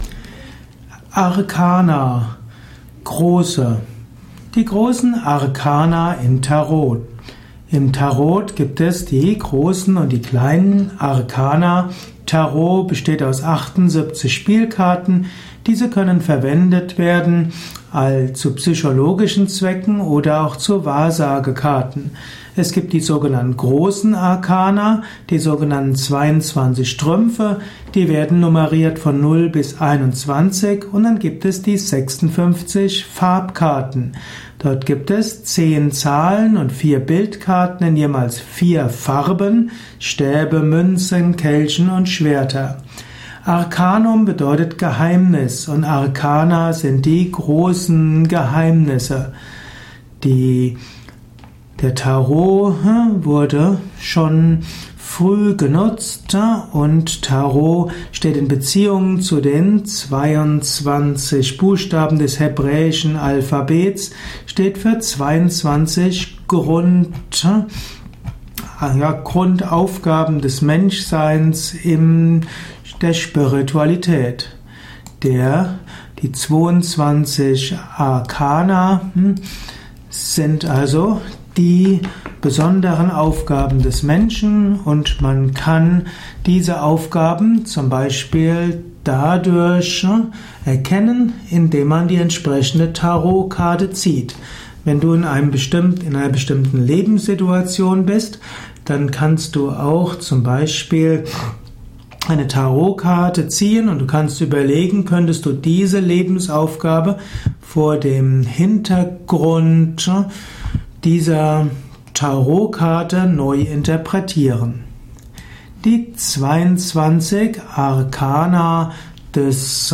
Dies ist die Tonspur eines Videos aus dem Youtube Esoterik-Kanal.
Wir wünschen dir viel Freude und Inspiration mit diesem Esoterik-Vortrag zum Thema Arkana Große.